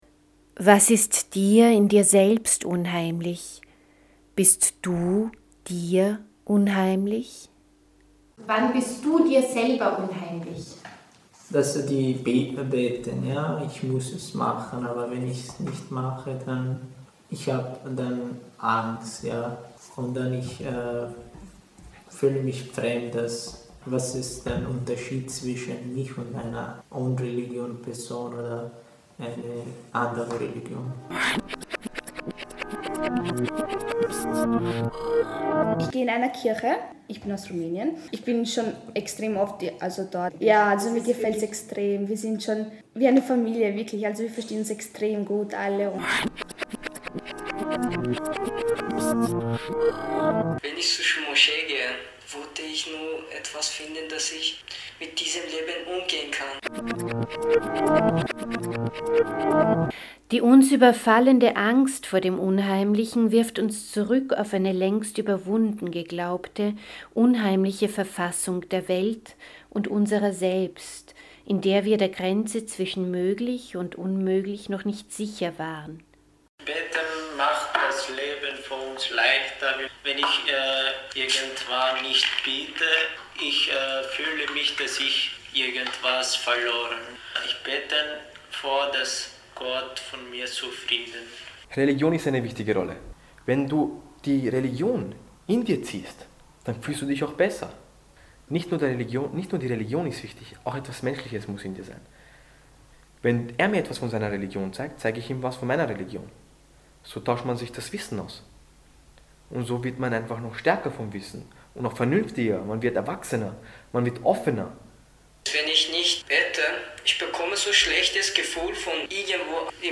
Dialoge zwischen Lehrlingen, Flüchtlingen und Wissenschafter*innen
Das Unheimliche – Religion / Audiofeature: